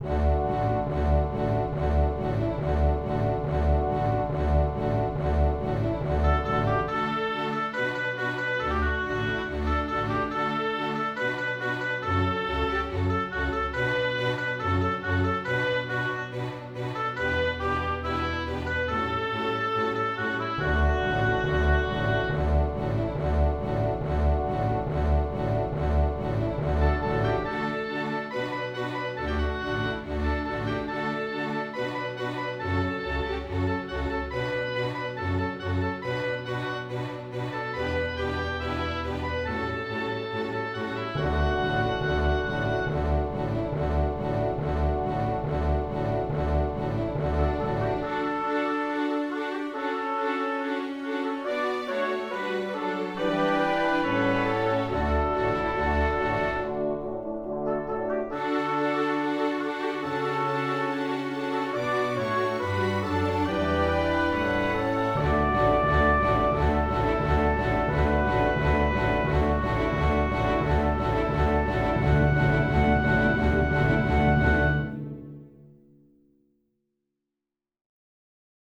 24 вокальных и инструментальных номера